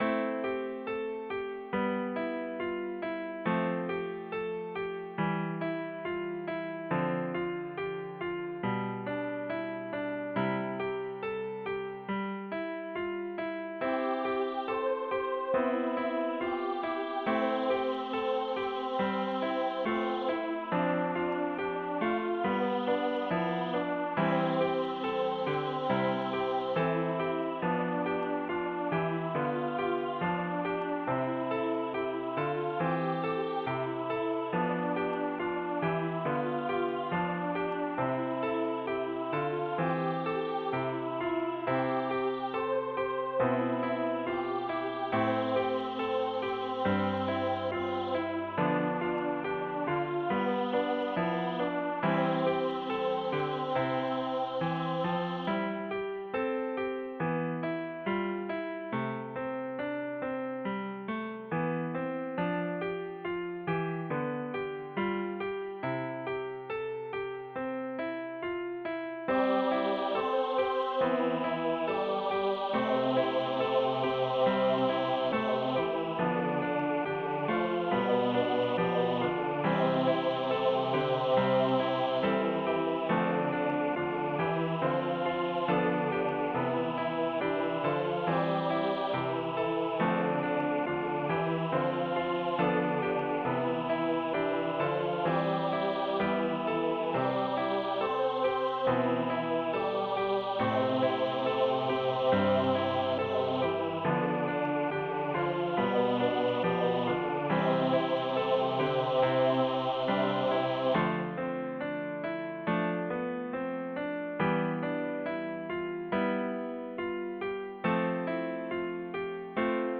easy choir arrangement